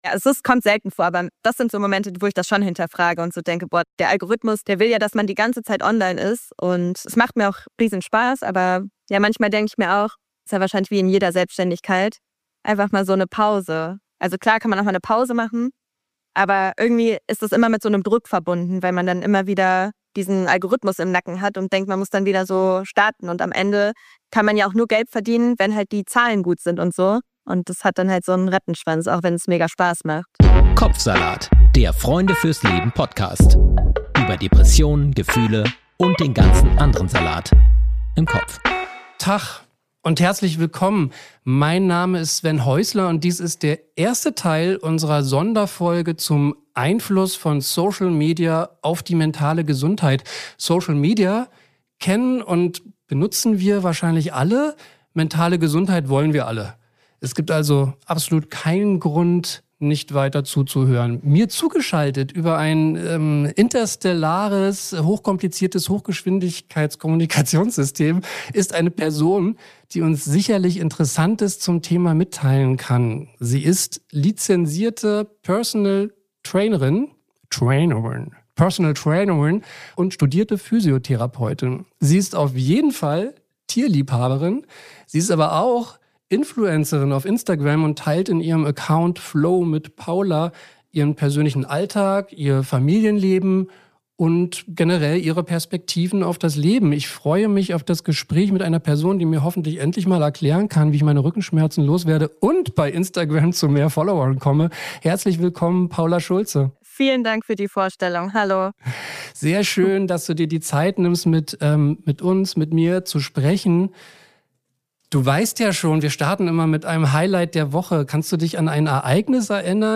Musik und Jingle